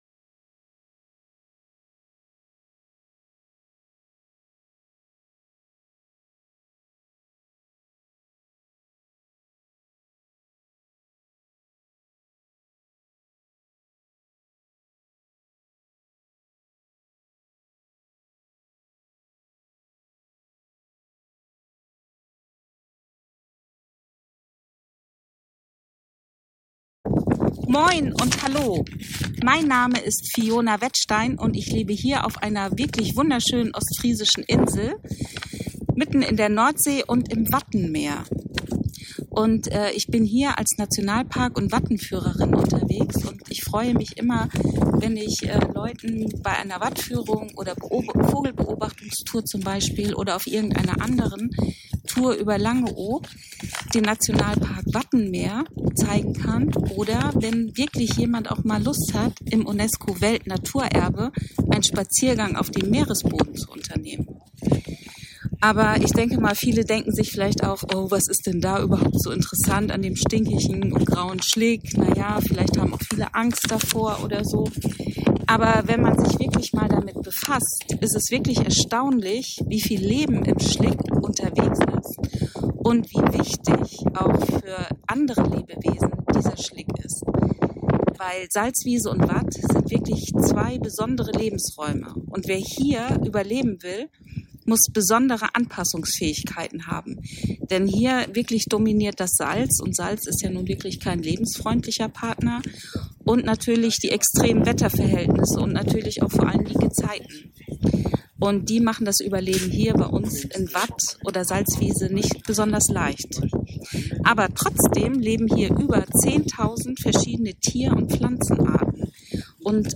(Leider war es sehr, sehr windig und stürmisch zur Aufnahmezeit. Die Hintergrundgeräusche konnten leider nicht ganz gefiltert werden. Sorry)